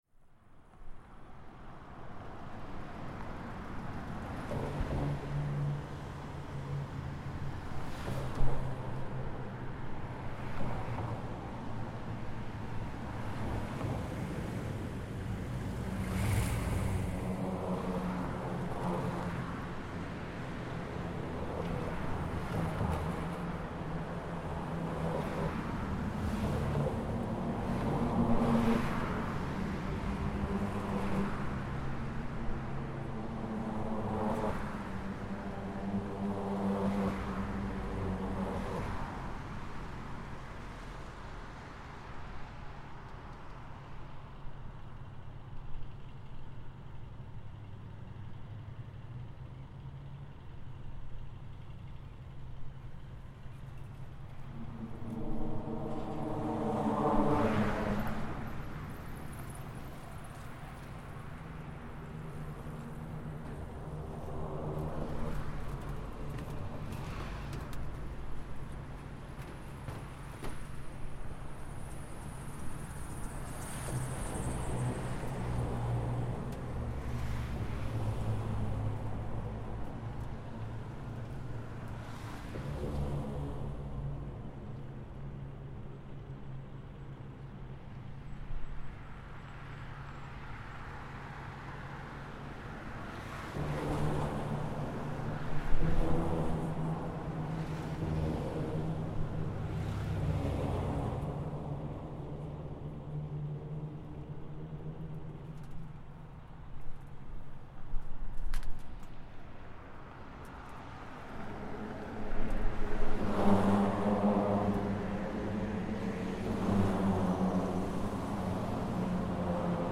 A rainy pre-dawn Sunday, on the former (now replaced) Johnson Street Bridge, Victoria, BC, Canada, 2012.
Traffic at varying sizes and speeds creates changing frequency hum, with intermittent footsteps, gulls and ambient city sounds.